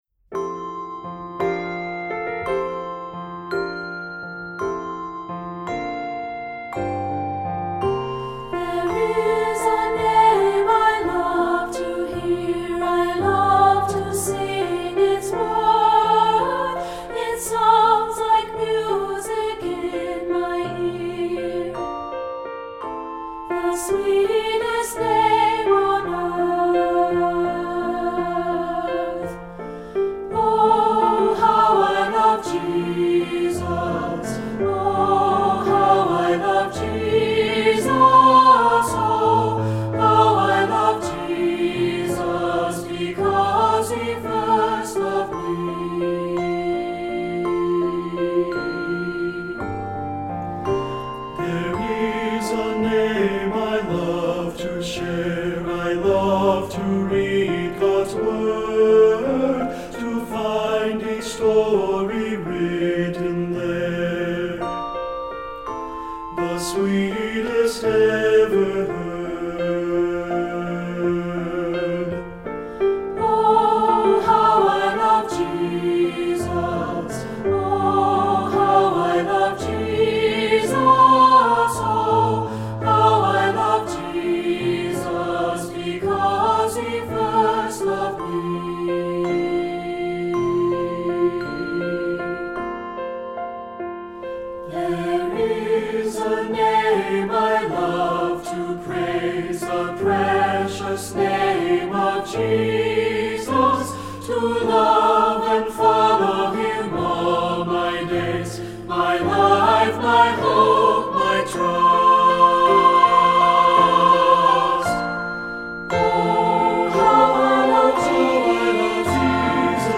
Voicing: Unison/Two